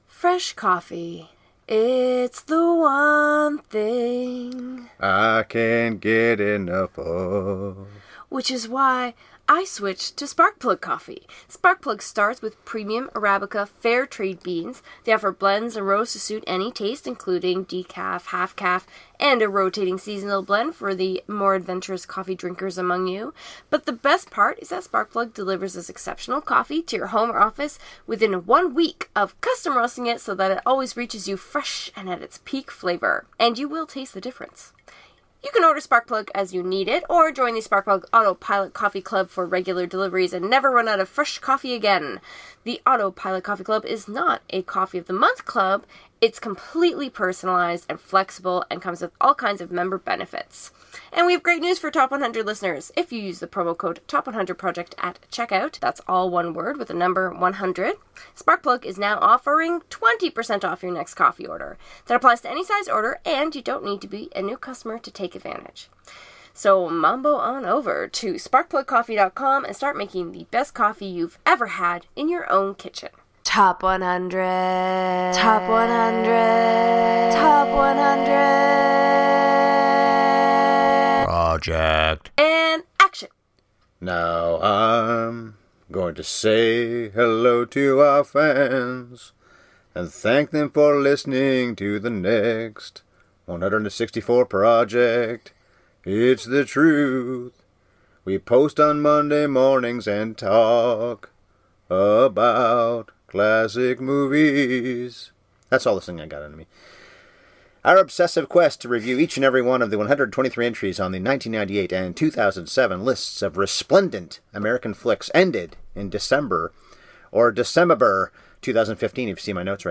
There’s a lot of discussion about that terrible line in this episode, plus there’s more singing than we’ve done in a long time.